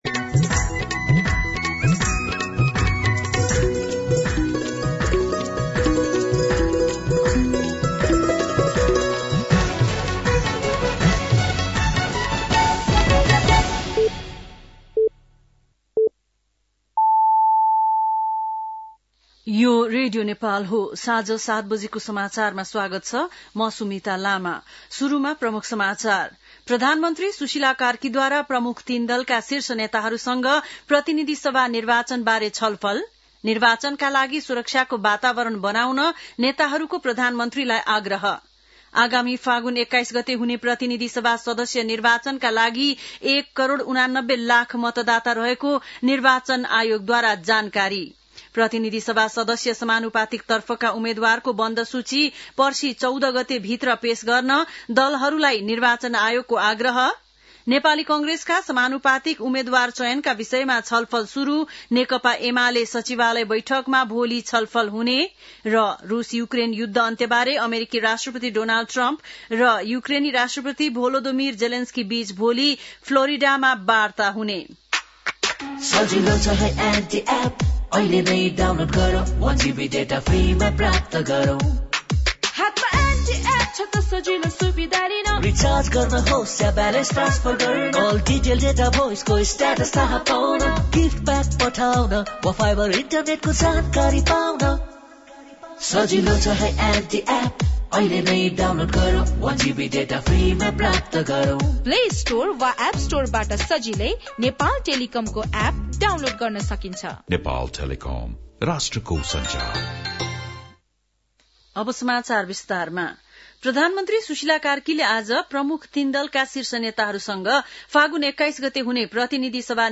बेलुकी ७ बजेको नेपाली समाचार : १२ पुष , २०८२
7.-pm-nepali-news-1-3.mp3